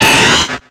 Cri de Papinox dans Pokémon X et Y.